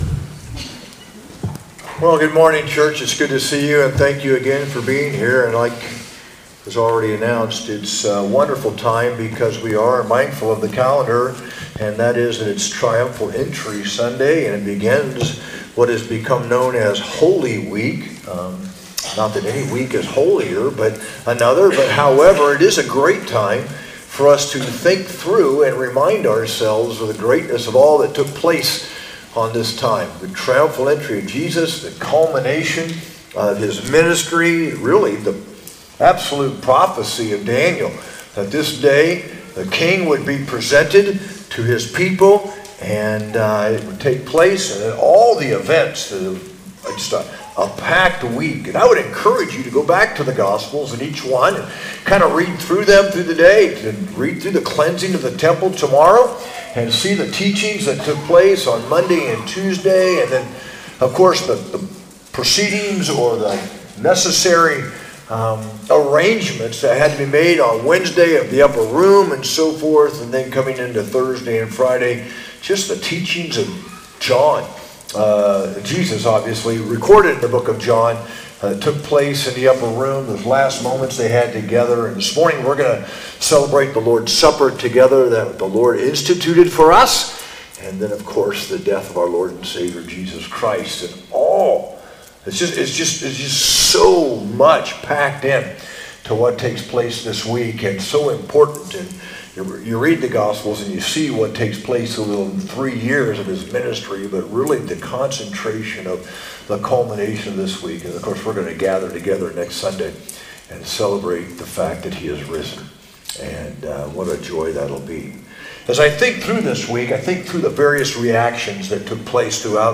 Sunday Morning |Easter Message
sermon-4-13-25.mp3